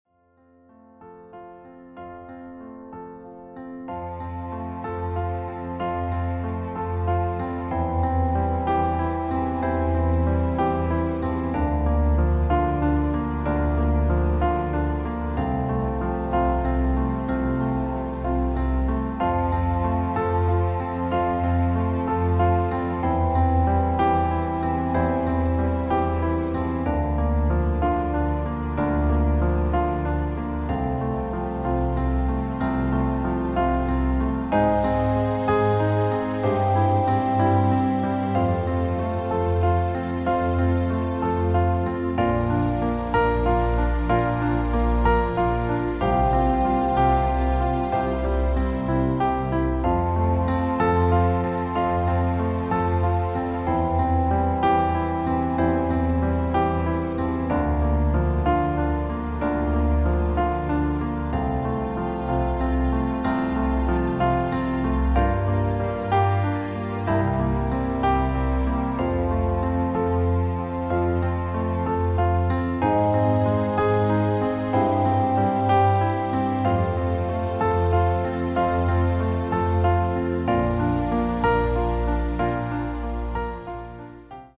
• Tonart: D Dur (Originaltonart ), E Dur, F Dur
• Art: Klavierversion mit Streichern
Demo in F Dur:
• Das Instrumental beinhaltet NICHT die Leadstimme